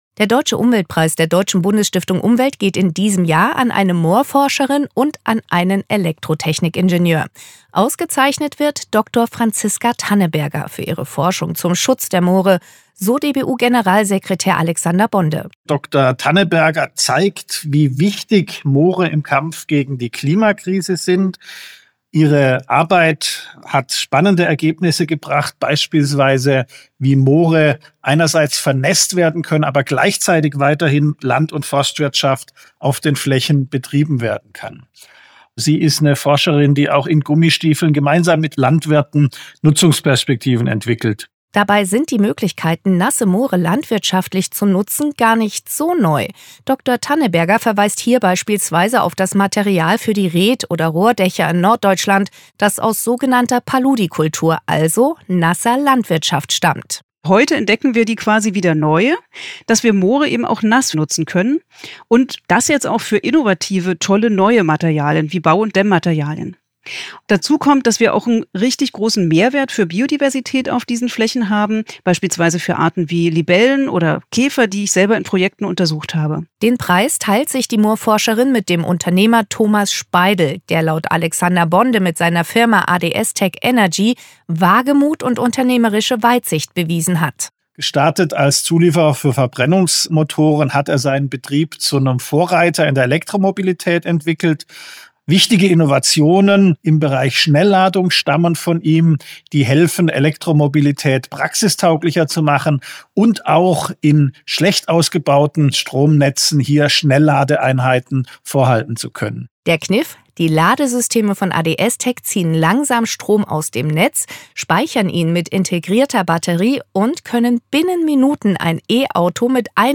Radiobeitrag
dbu_media-Radiobeitrag-Deutscher-Umweltpreis-2024.mp3